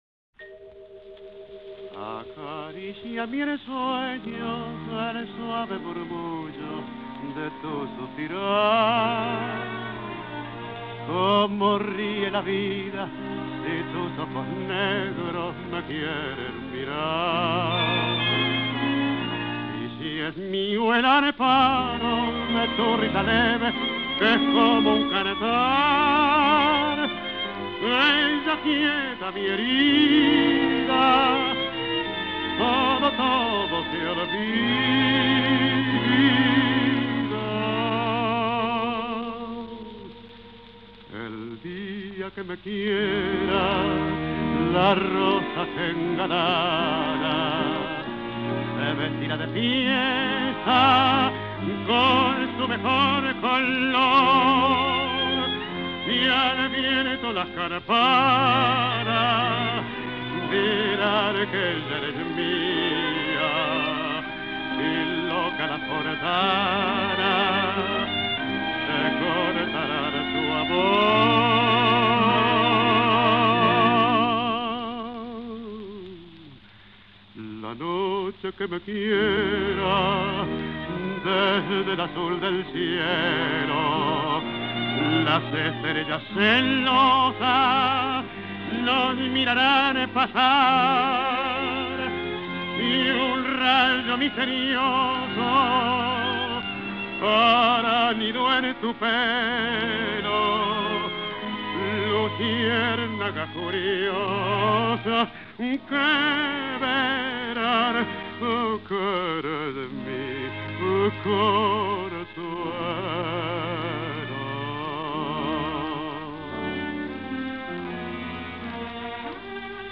Letra y música